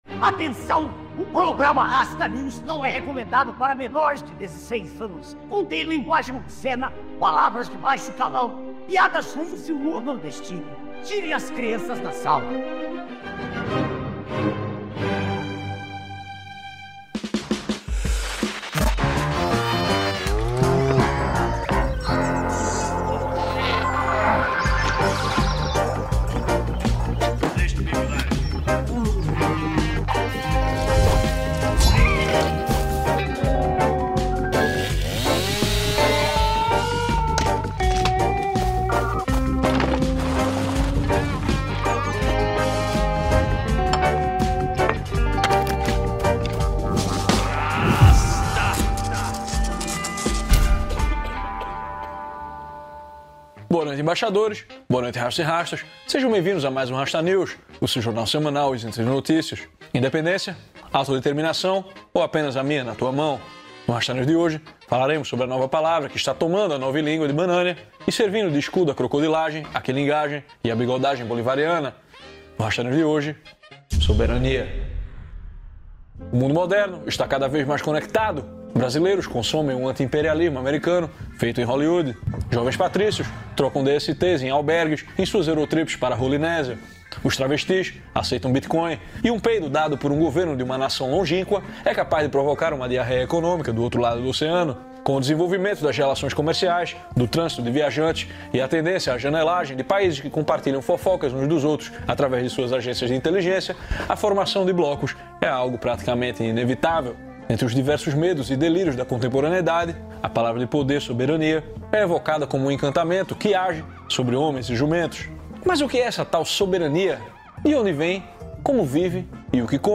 apresentando o programa diretamente das montanhas Adirondack, ao norte do estado de Nova York.